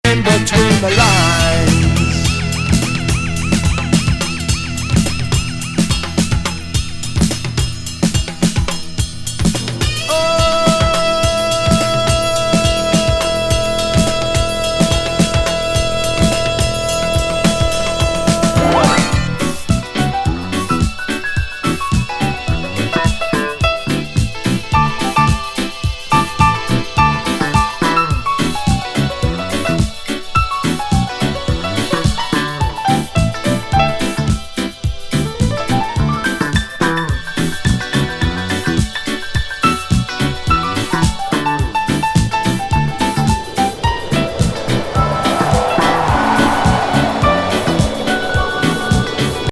FUNKYな傑作。